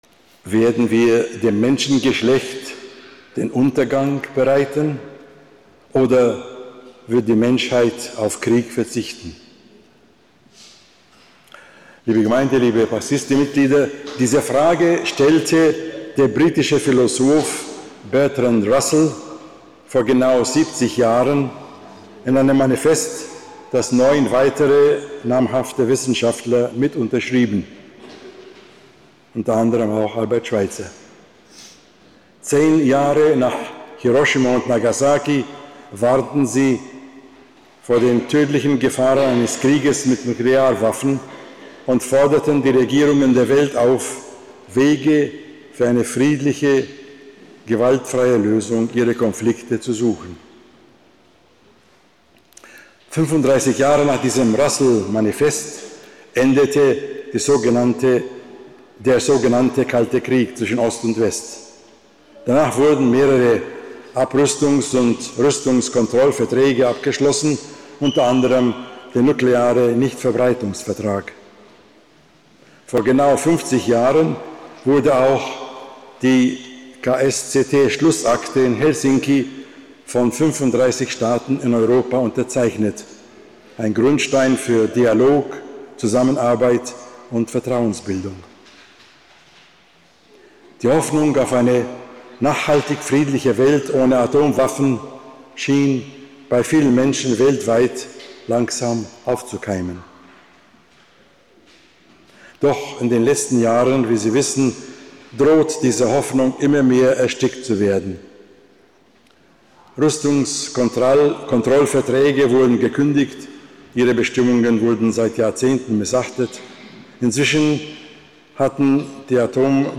Predigt zum Hiroshima-Gedenkgottesdienst 2025